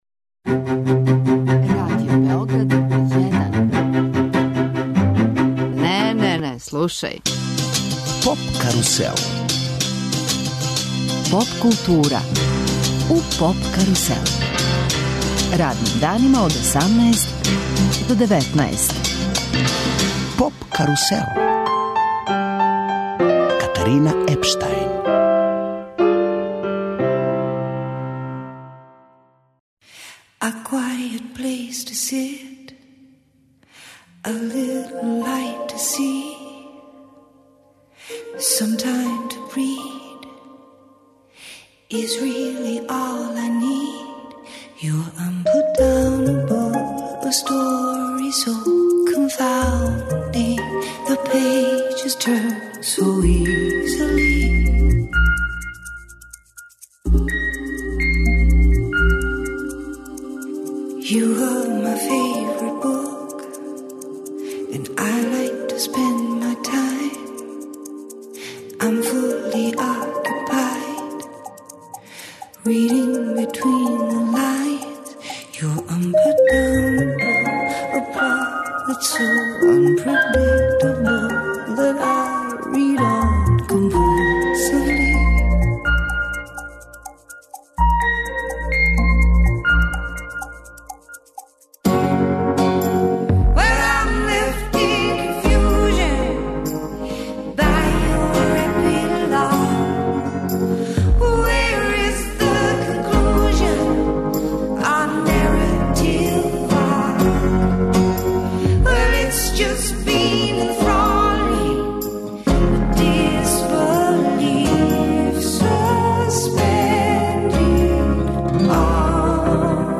Представљамо београдски инди поп састав Битипатиби, који је у оквиру своје регионалне турнеје одржао успешан концерт у загребачкој Творници културе. Наша гошћа је